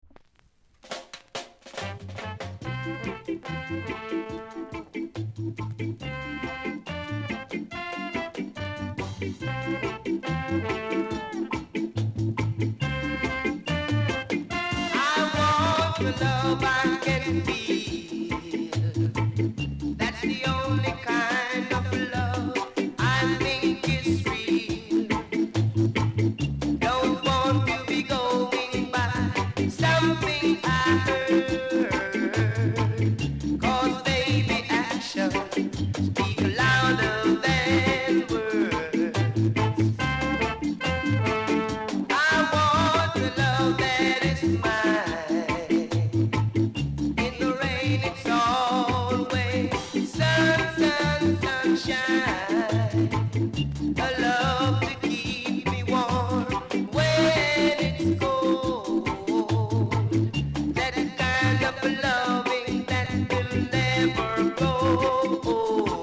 ※若干音が濁って聴こえます。